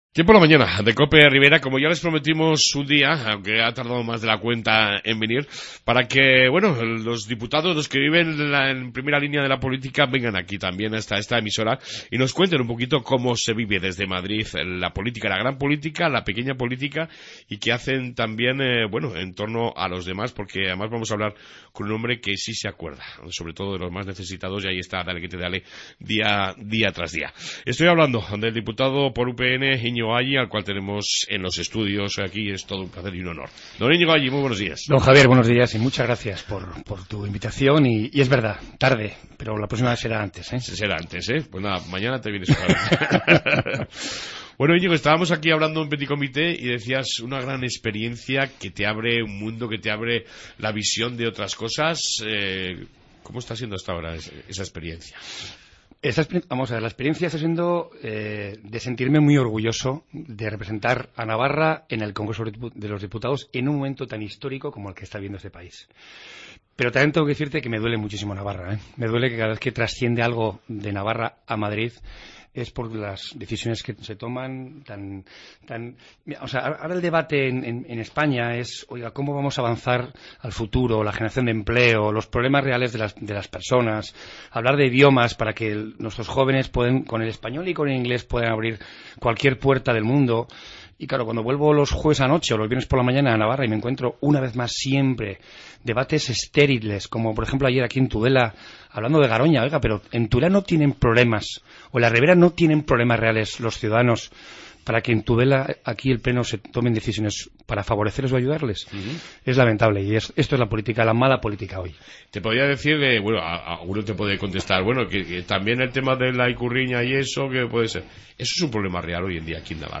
Redacción digital Madrid - Publicado el 28 feb 2017, 19:16 - Actualizado 19 mar 2023, 03:35 1 min lectura Descargar Facebook Twitter Whatsapp Telegram Enviar por email Copiar enlace Hoy ha visitado nuestros estudios el diputado de UPN, Iñigo Alli, para hablar de su trabajo desde el congreso.